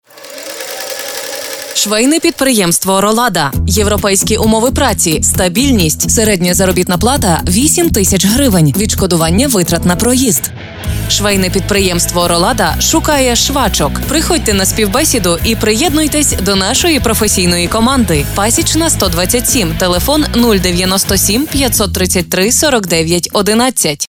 Ukrainisch, ukrainian, native speaker, Mutterspachler, vertauerlich, empathic, empathysch
Sprechprobe: Industrie (Muttersprache):